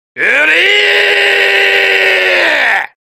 dios-wryyy-voice-clip.mp3